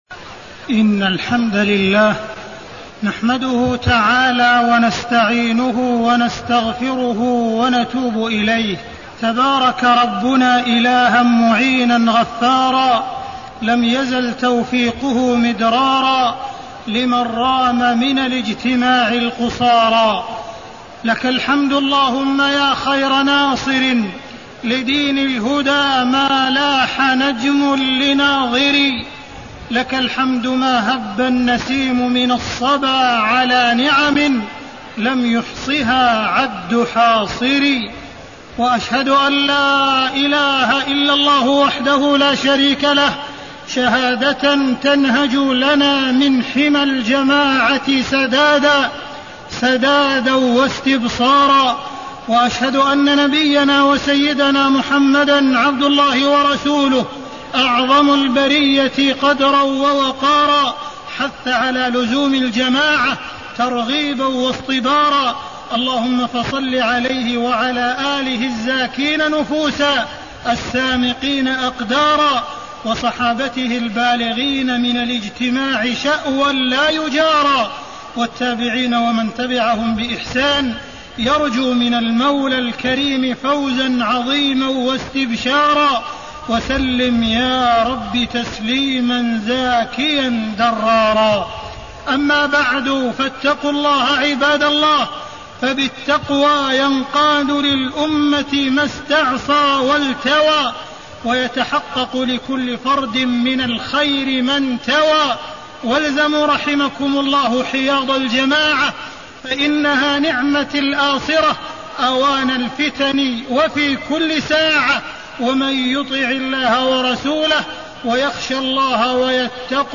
تاريخ النشر ٢٥ جمادى الأولى ١٤٣٢ هـ المكان: المسجد الحرام الشيخ: معالي الشيخ أ.د. عبدالرحمن بن عبدالعزيز السديس معالي الشيخ أ.د. عبدالرحمن بن عبدالعزيز السديس لزوم جماعة المسلمين